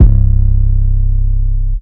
808 [ swim ].wav